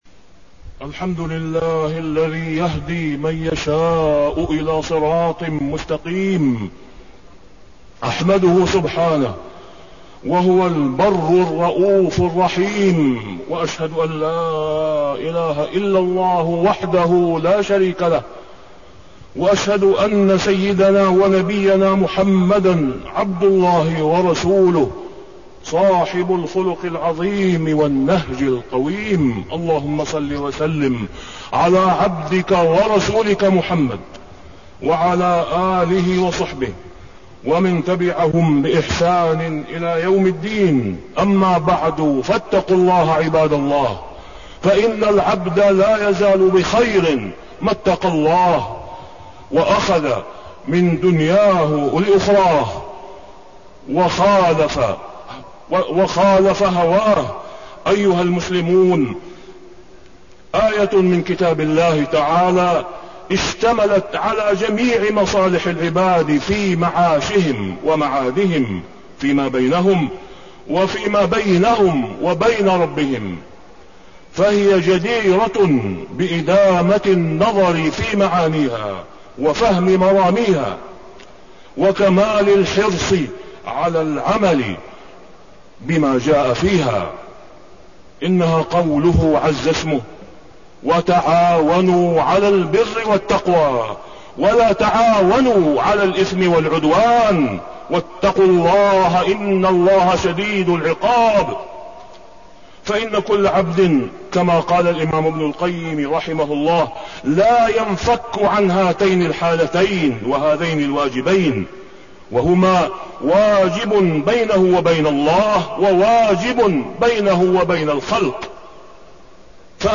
تاريخ النشر ٦ رجب ١٤٣١ هـ المكان: المسجد الحرام الشيخ: فضيلة الشيخ د. أسامة بن عبدالله خياط فضيلة الشيخ د. أسامة بن عبدالله خياط البر والتقوي